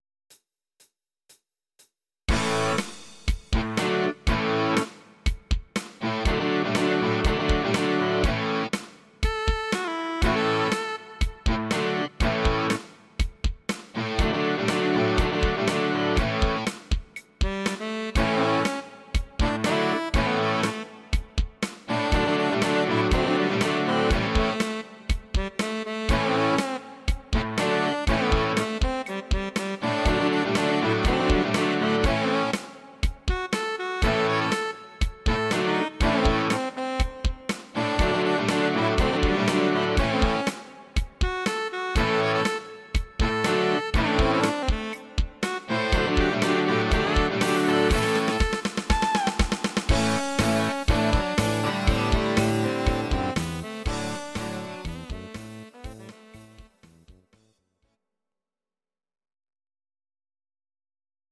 Audio Recordings based on Midi-files
Rock, 1970s